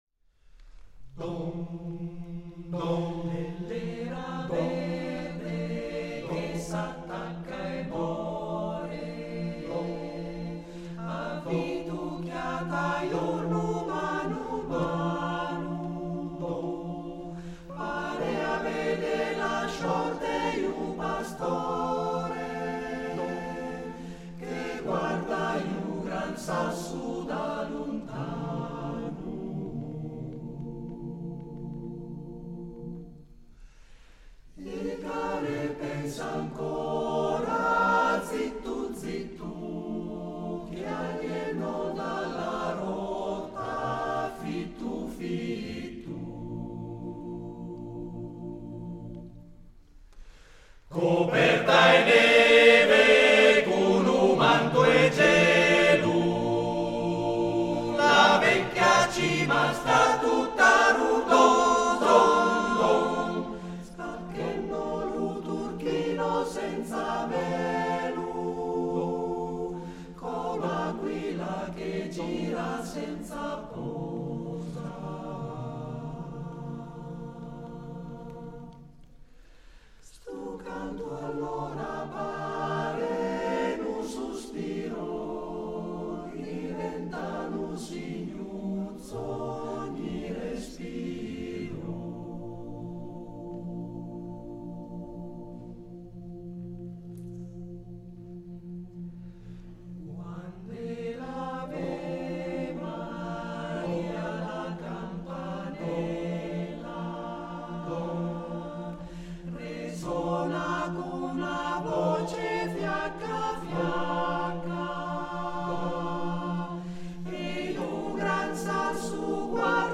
Arrangiatore: Allia, Mario (Armonizzatore)
Esecutore: Coro CAI Uget
: Registrazione live